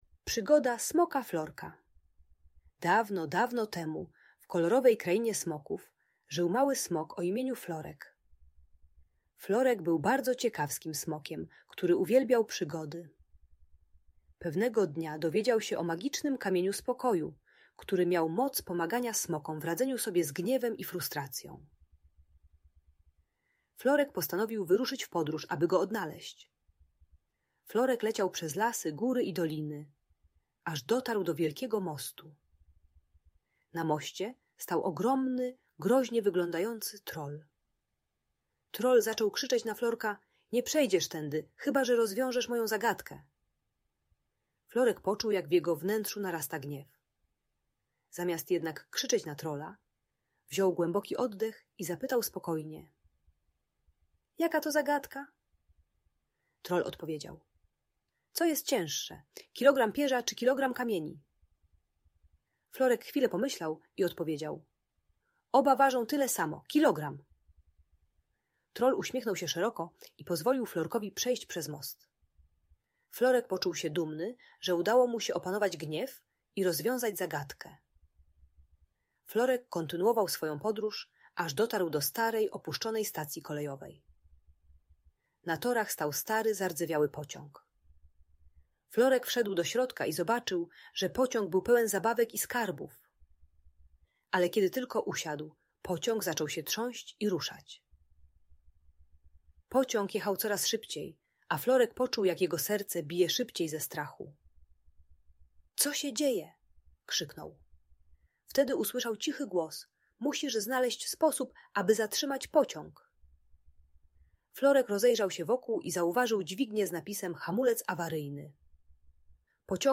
Przygoda Smoka Florka - Opowieść o spokoju i odwadze - Audiobajka